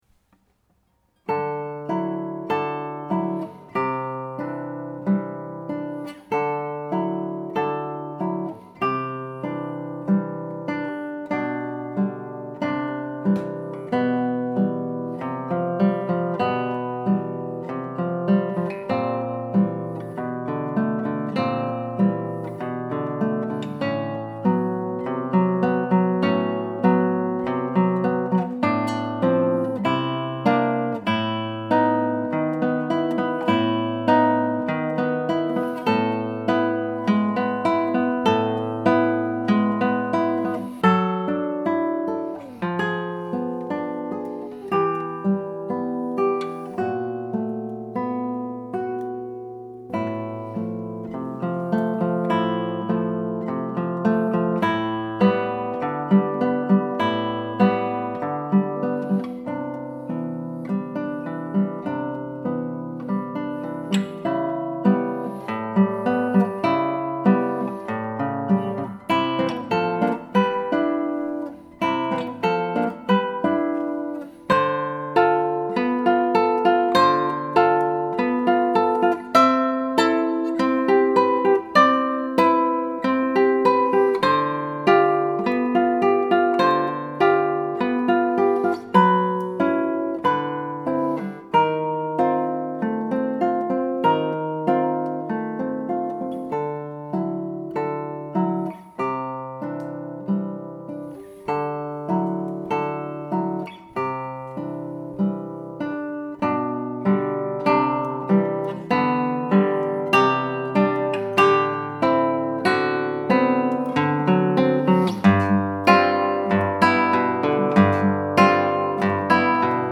ALT-CLASSICAL